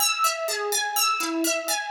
Index of /musicradar/shimmer-and-sparkle-samples/125bpm
SaS_Arp01_125-E.wav